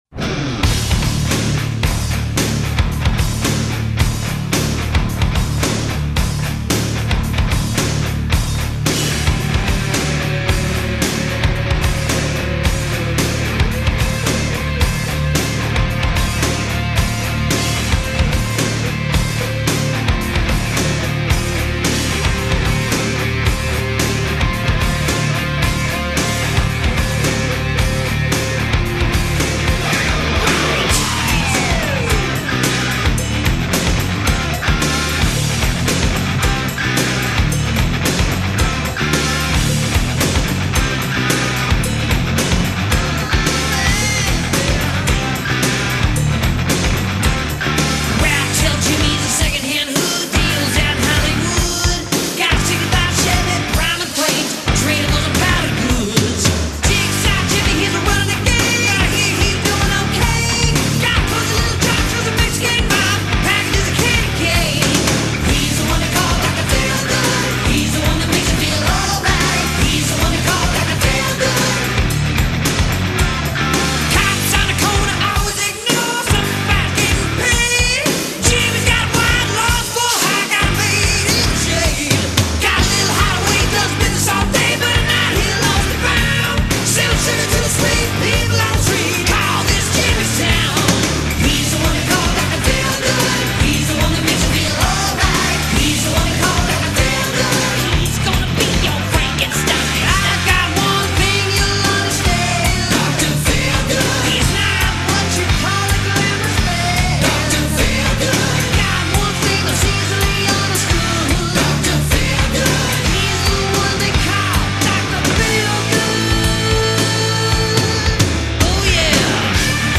Genre:heavy metal, glam metal Year